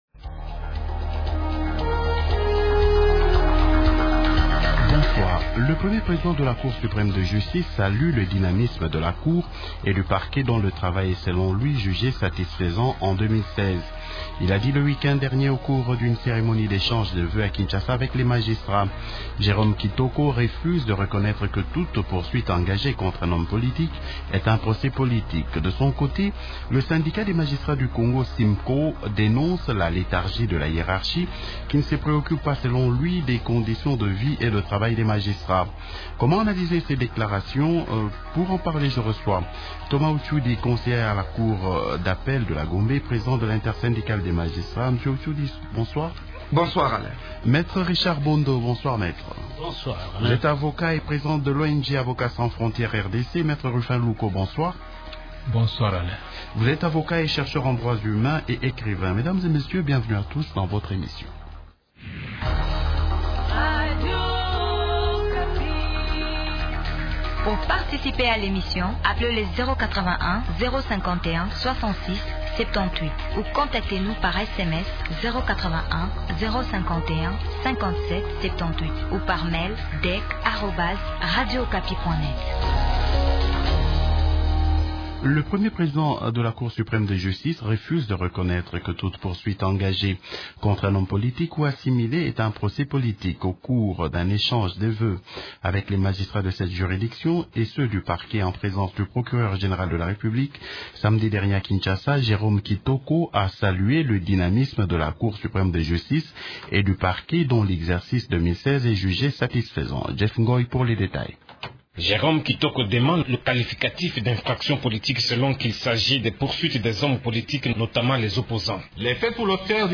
Comment analyser ces déclarations ? Participent au débat de ce soir